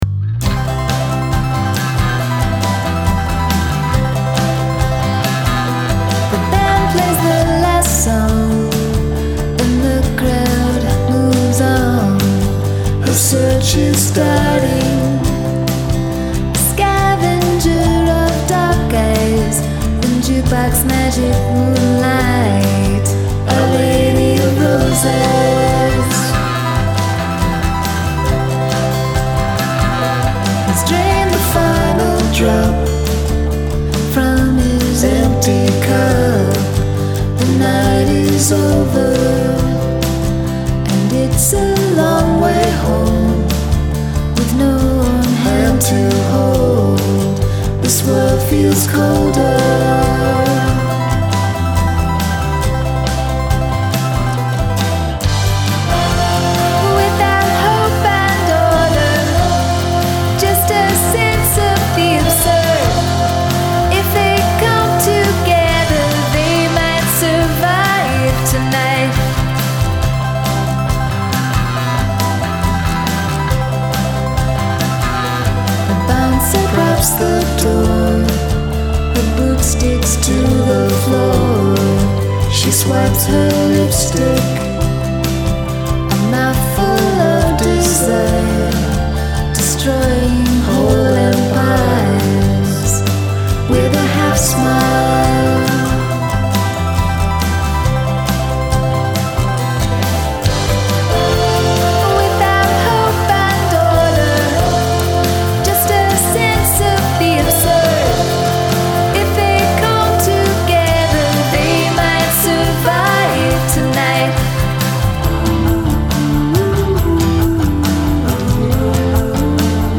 Great harmonizing and production.
Cool bass tone.